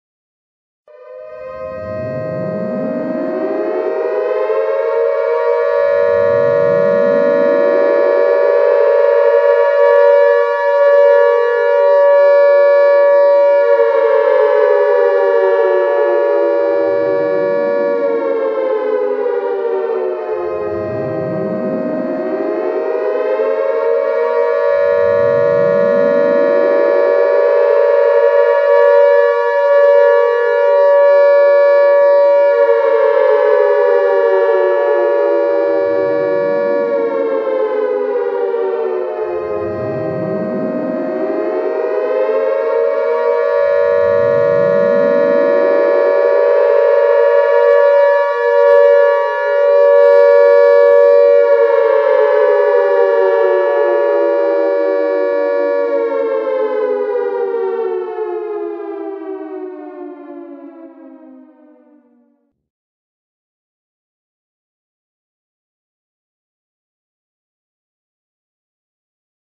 Szirena.mp3